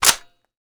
m82_boltforward.wav